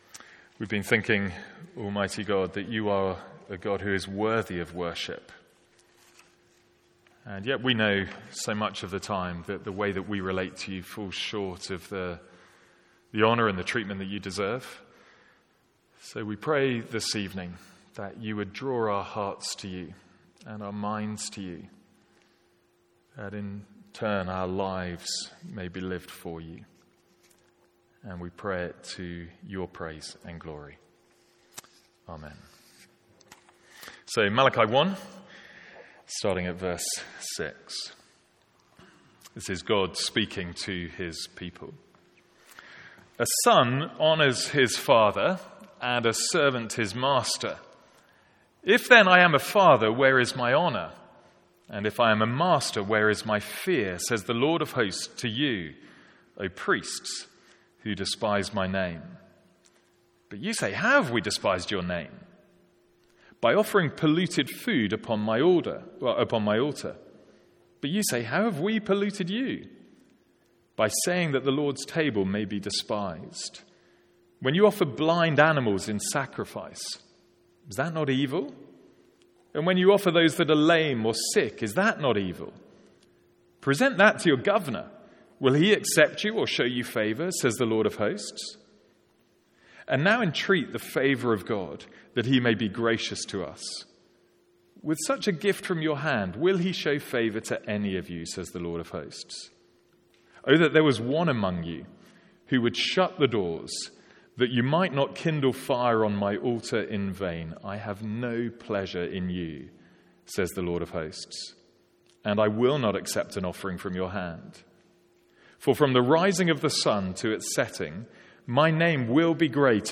Sermons | St Andrews Free Church
From our evening service in Malachi.